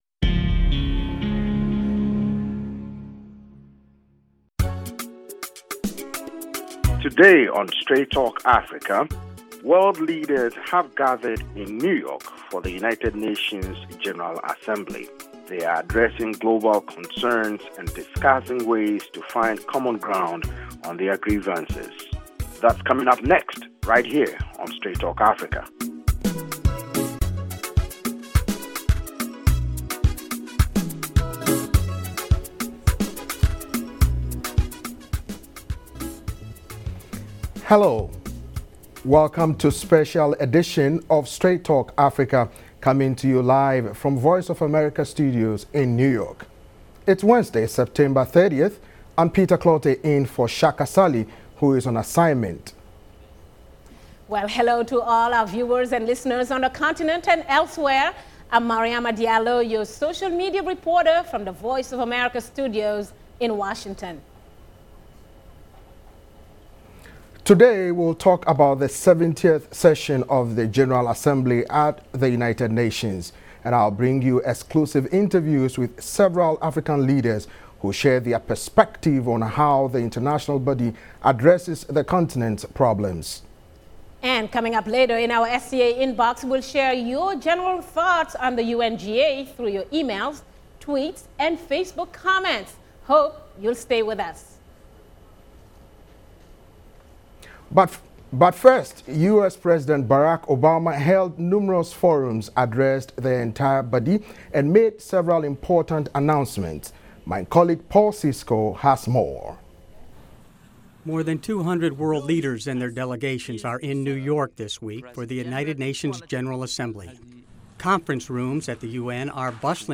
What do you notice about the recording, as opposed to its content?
live from VOA's New York studio